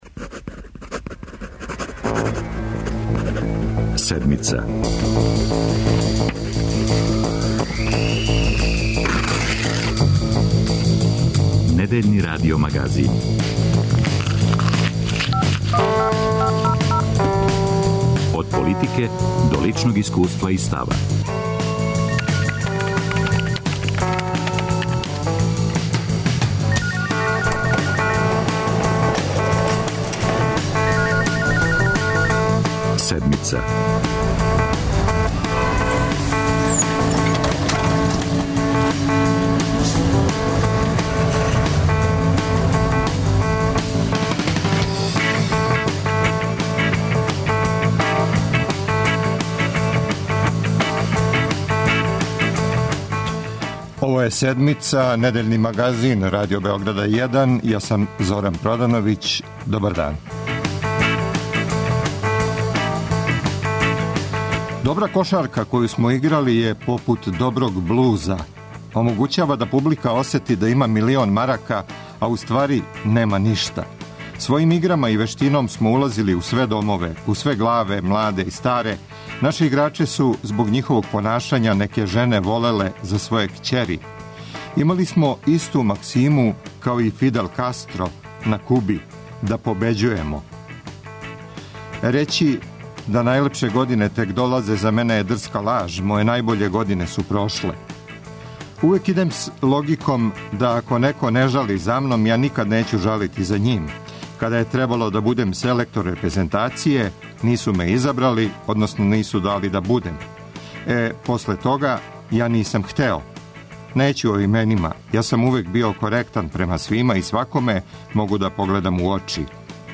Добра кошарка је попут квалитетног блуза. Да ли ћемо на јесен слушати клупски или репрезентативни блуз за Седмицу говори Божидар Маљковић.